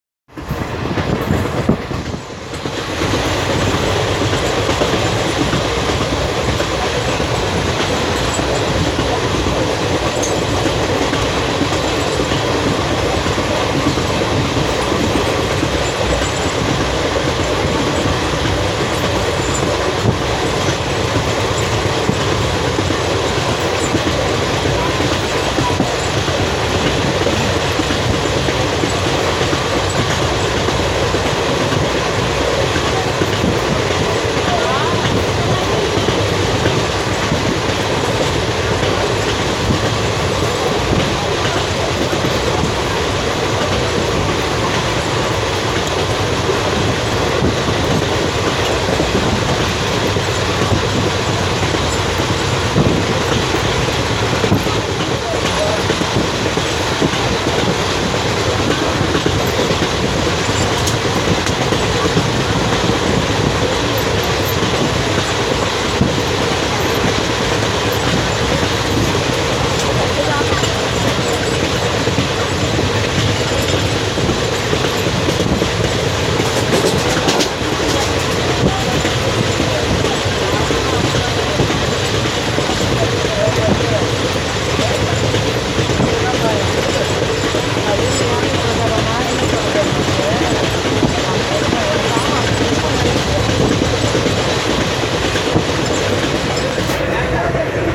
Now that there wasn’t much slack left in our schedule, we sped away in earnest; again with rhythmic flat wheel sounds! 😛
11044-blasting-after-shahabad.mp3